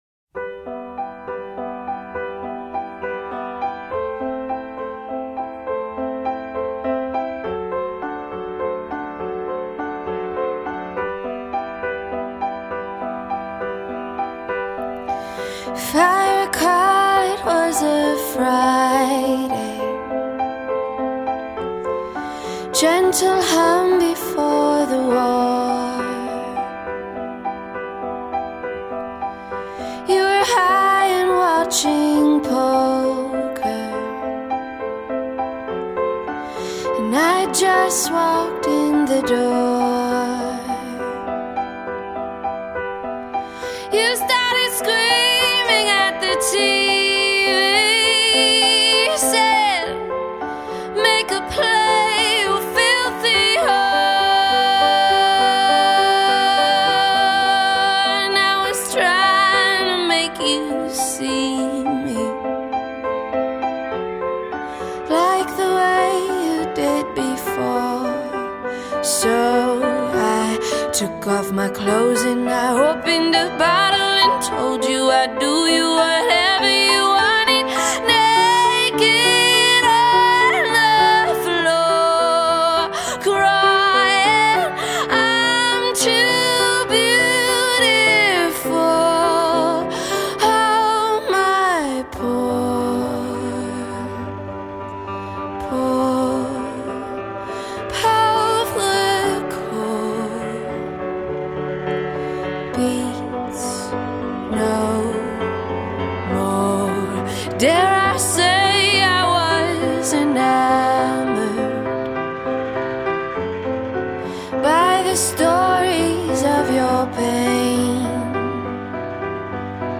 aching vocals
rolling piano